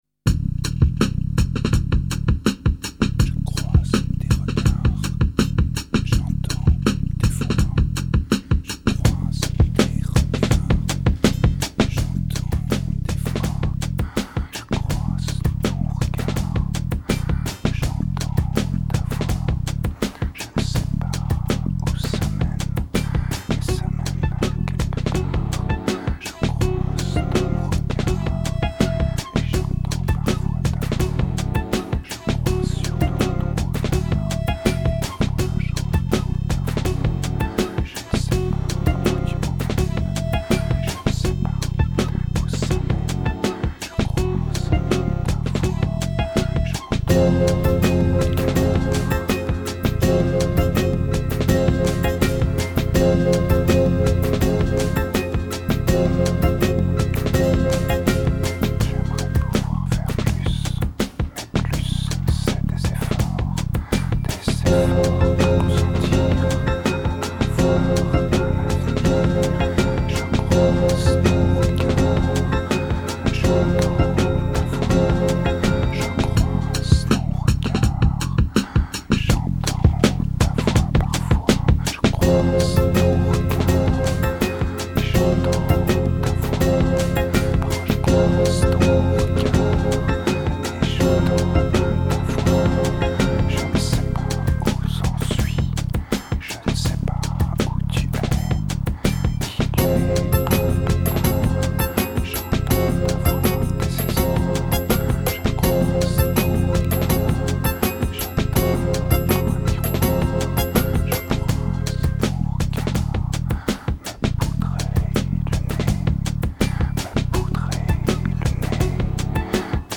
turned out as yet another dirty electro release.